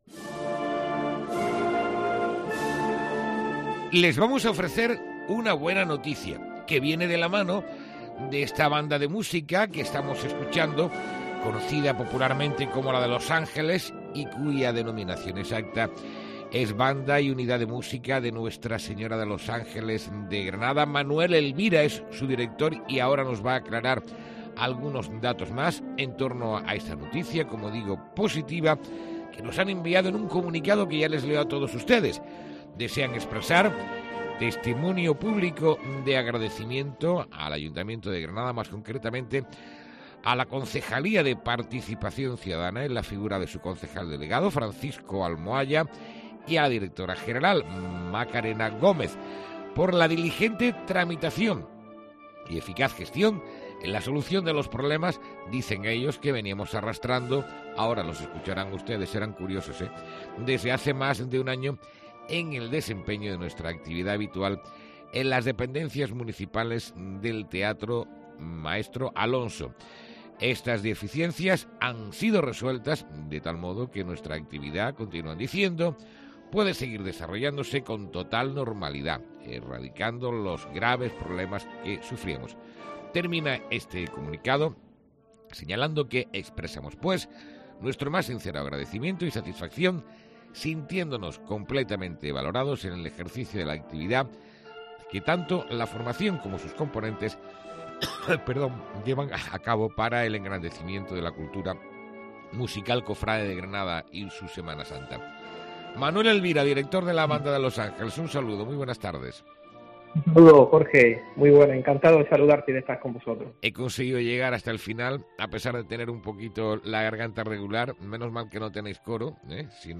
ENTREVISTA|| La Banda de los Ángeles resuelve uno de sus principales problemas